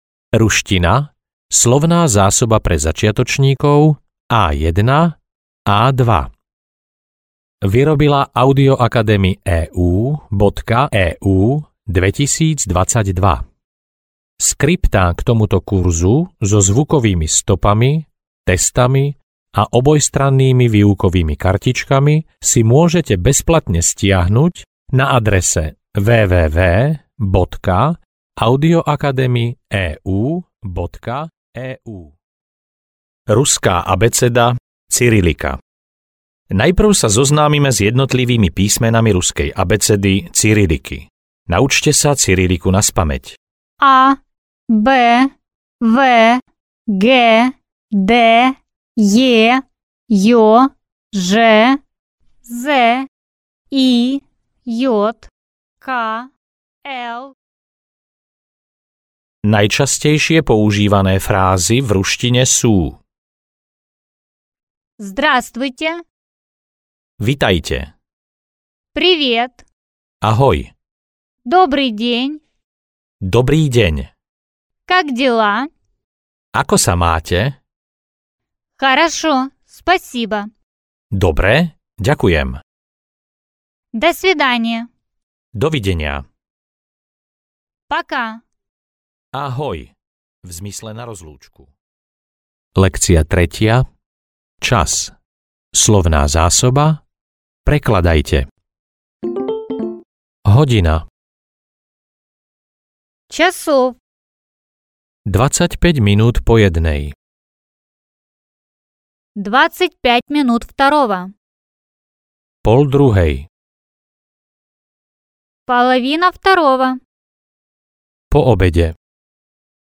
Ruština pre začiatočníkov A1-A2 audiokniha
Ukázka z knihy